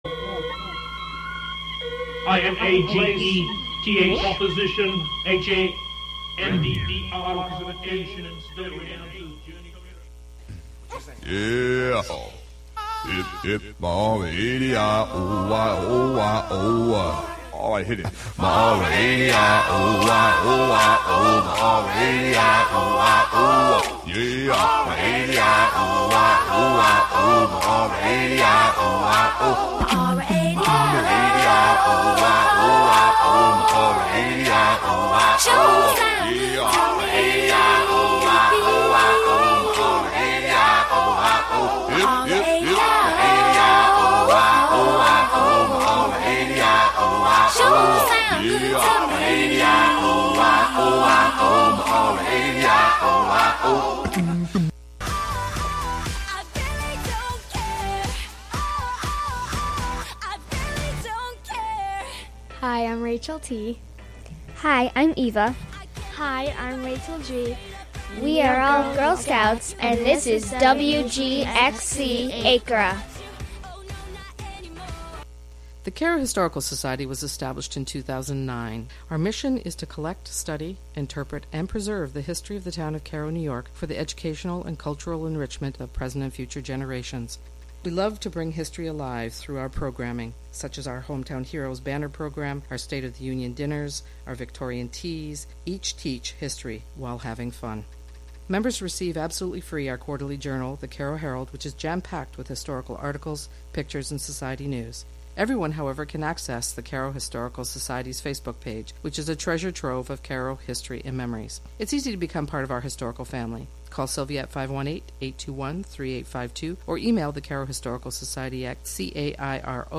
This episode was recorded during the coronavirus pandemic of 2020.